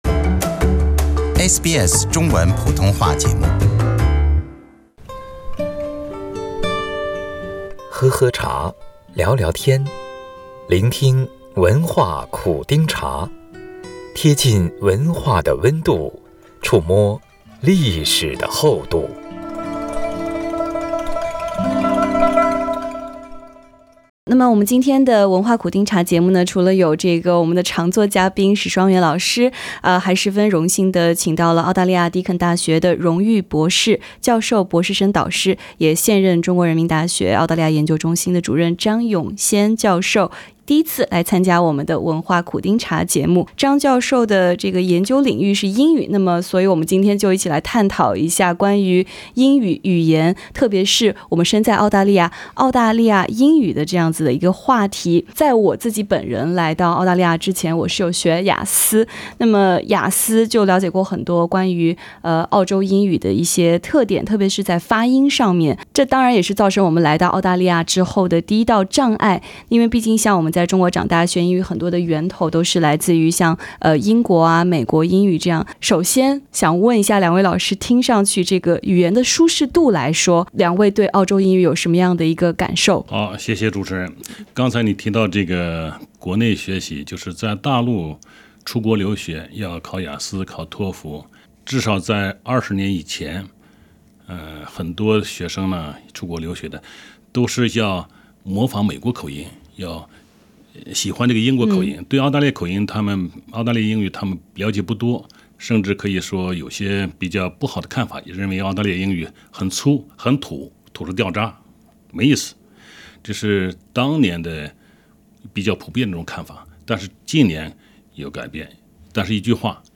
SBS电台《文化苦丁茶》每周五早上澳洲东部时间早上8:15播出，每周日早上8:15重播。